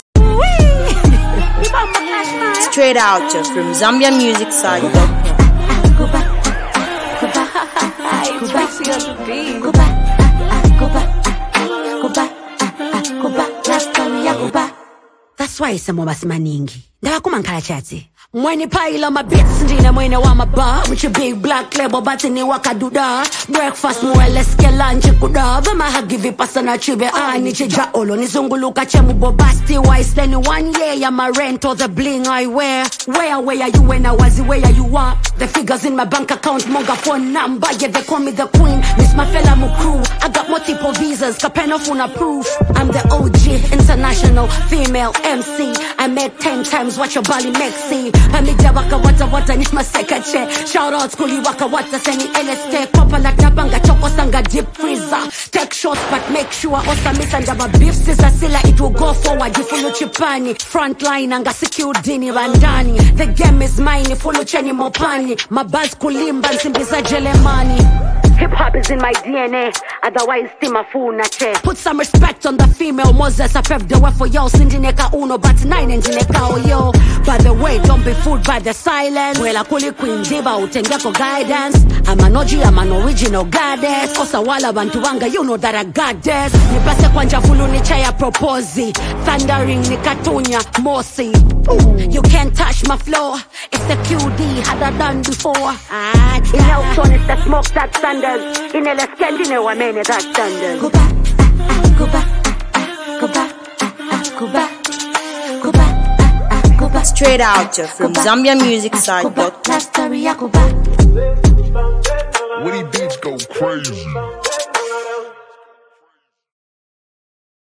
it's a nice tune with a great vibe